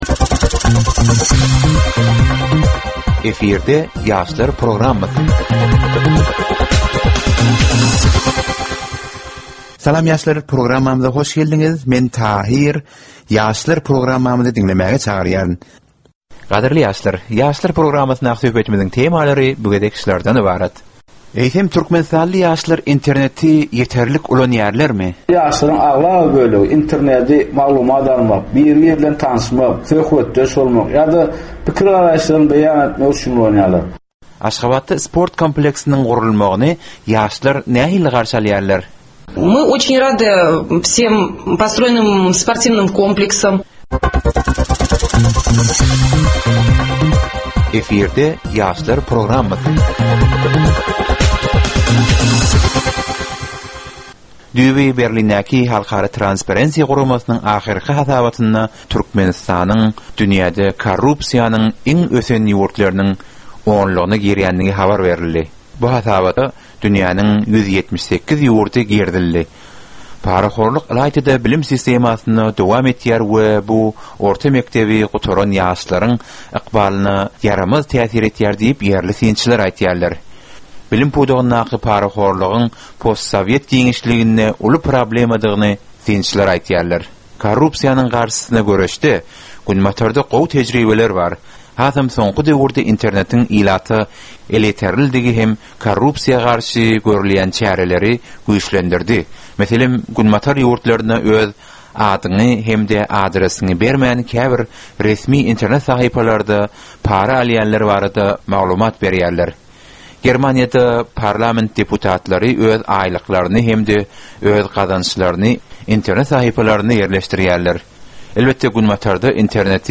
Türkmen we halkara yaşlarynyň durmuşyna degişli derwaýys meselelere we täzeliklere bagyşlanylyp taýýarlanylýan ýörite gepleşik.
Gepleşigiň dowamynda aýdym-sazlar hem eşitdirilýär.